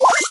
jess_reload_02.ogg